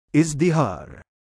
母音記号あり：اِزْدِهَار [ ’izdihār ] [ イズディハール ]
femalename_2izdihaar.mp3